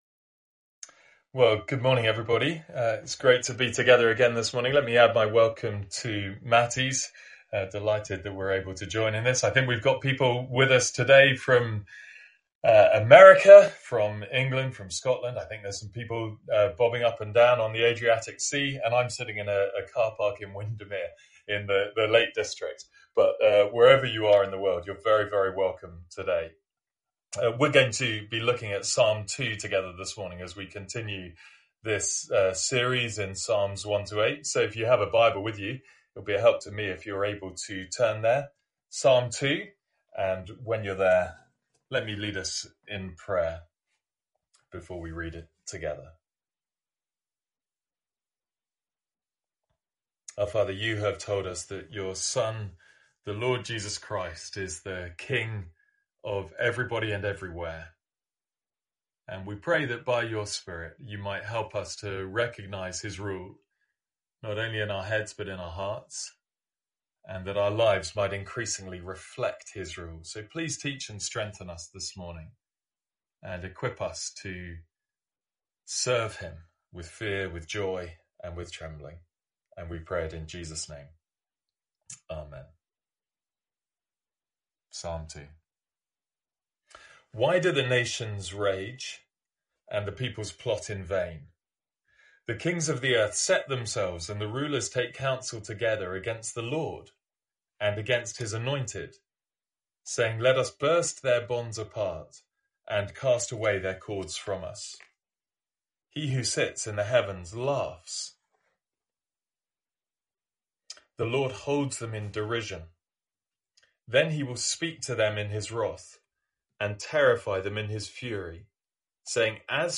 Sermons | St Andrews Free Church
From our morning series in the Psalms.